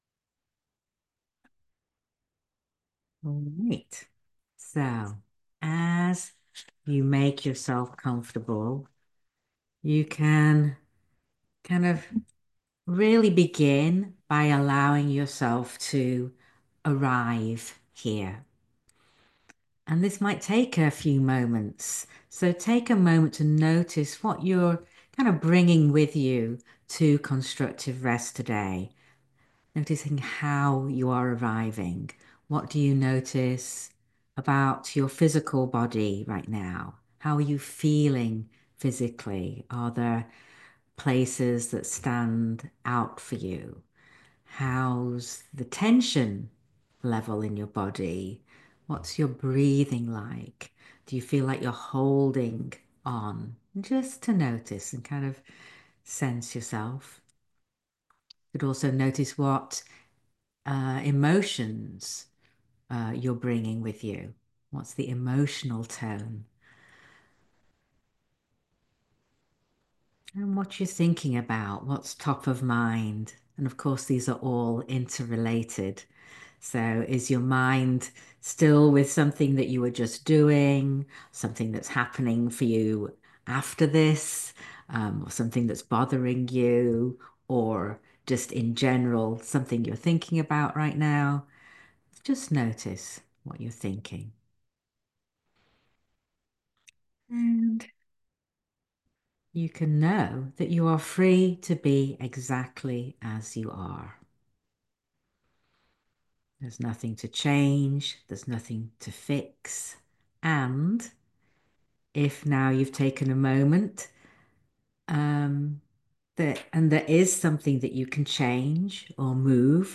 talk given during a live session on April 30, 2025. This talk has a gentle focus on awareness and acceptance of ourselves.